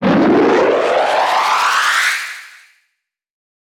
jet1.wav